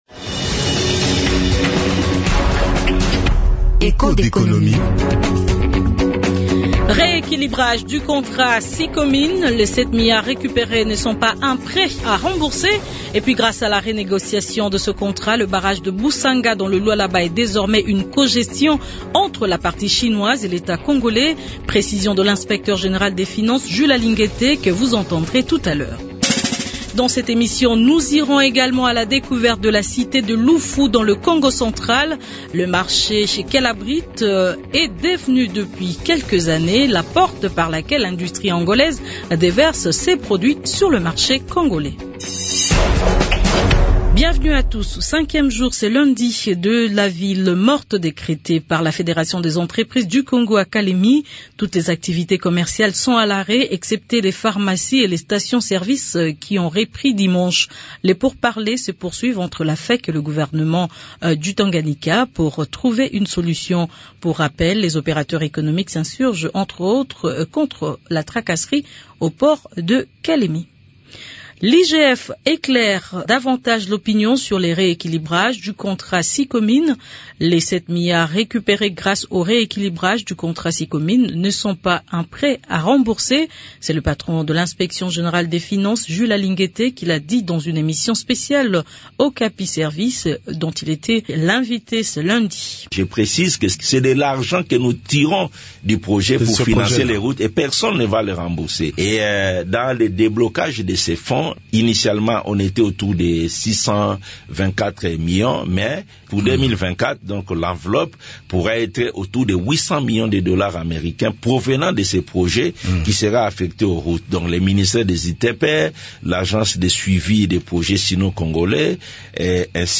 Le marché transfrontalier de Lufu, espace commercial situé dans le territoire de Songololo dans la province du Kongo Central est devenu depuis quelques années, la porte par laquelle l’industrie angolaise déverse ses produits sur le marché congolais. Radio Okapi vous propose une série de reportages sur l’agglomération de Lufu qui abrite ce marché transfrontalier. Pour commencer, Echos d’économie vous propose la carte postale de cette agglomération.